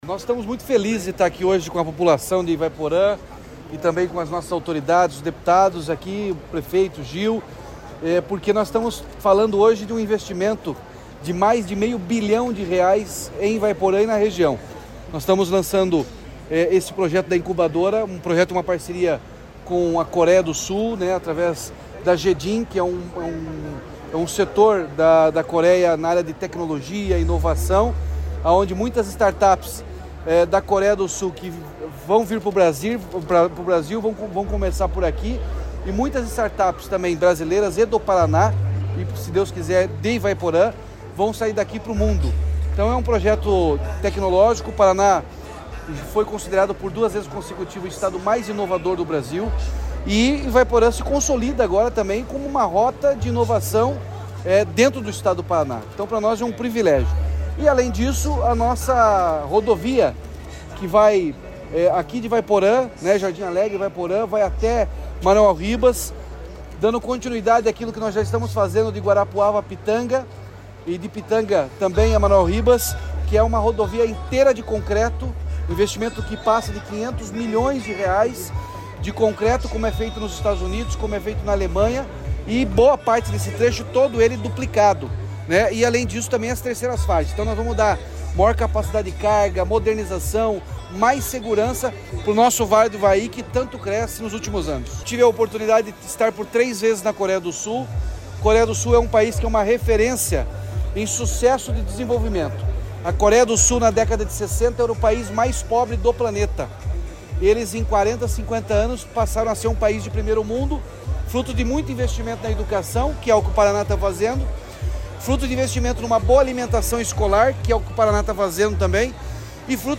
Sonora do governador Ratinho Junior sobre a Parceria Paraná-Coreia do Sul
RATINHO JUNIOR - IVAIPORÃ.mp3